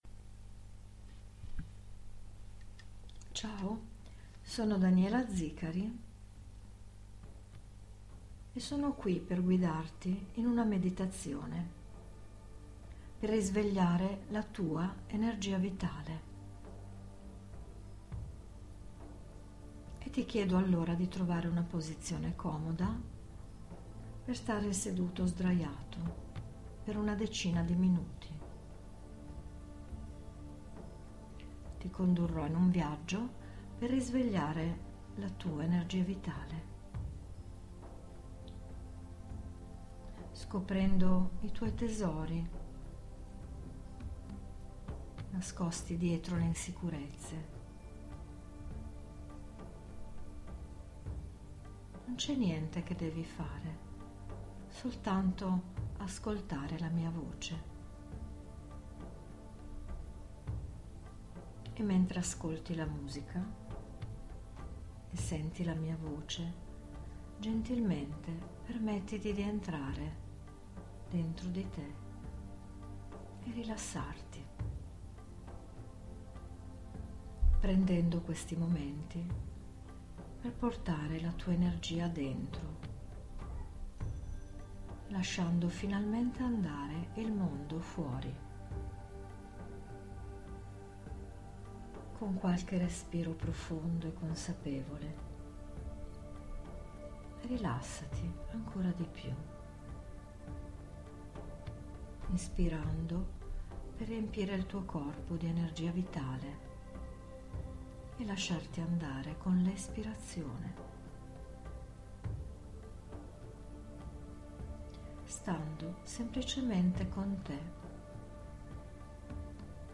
Meditazione guidata per ricaricare ENERGIA VITALE - Scarica Gratis MP3